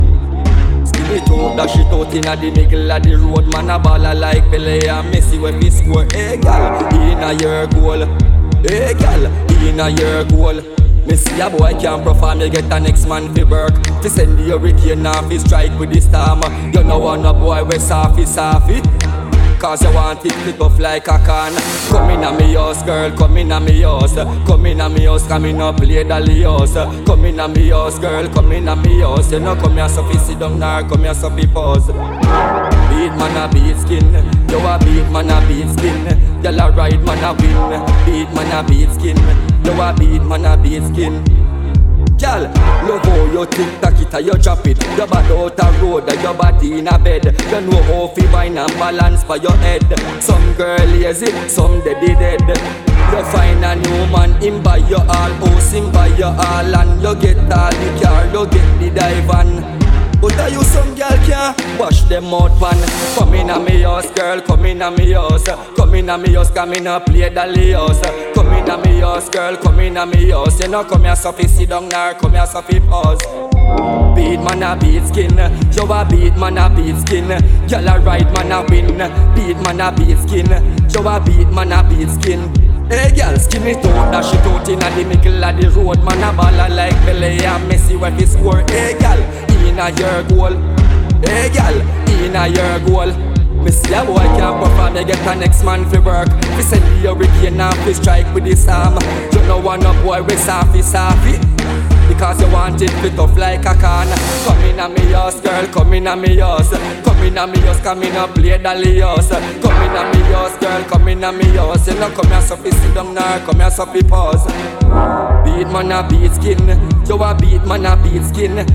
New Release Bass / Dubstep Dancehall